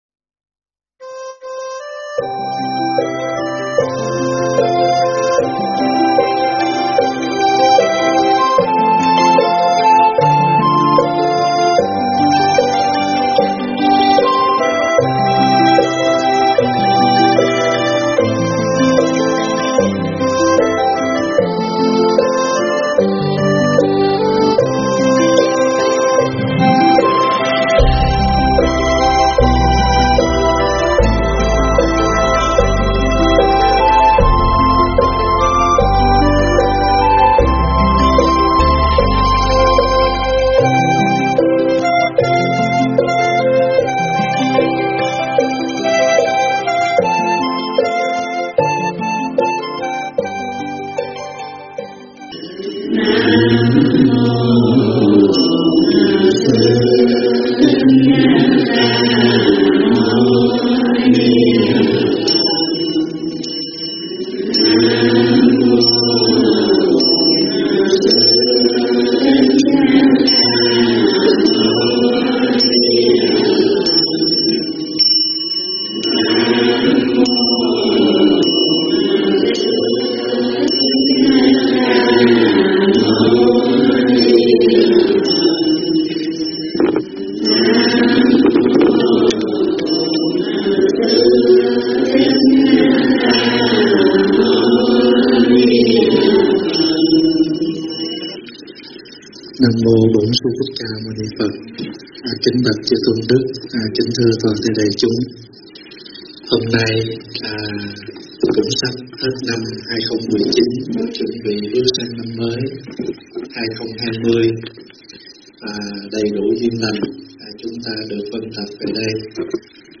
Mp3 pháp thoại